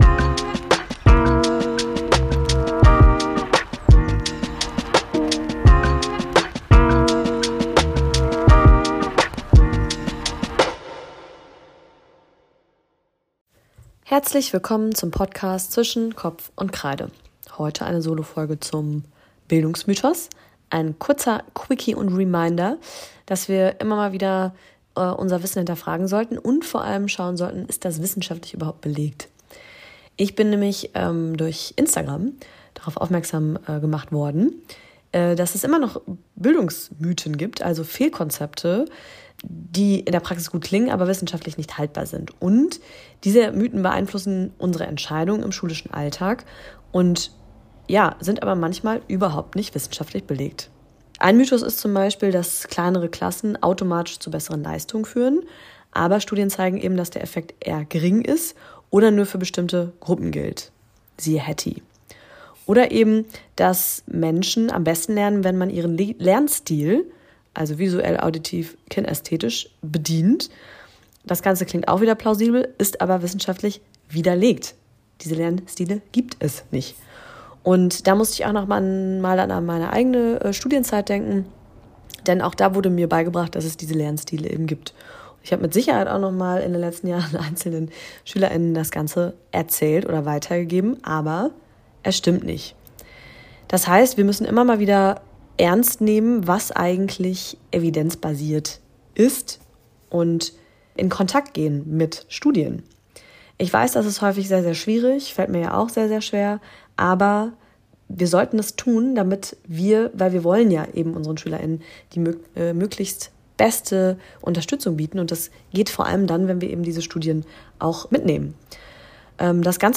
In dieser kurzen Solo-Folge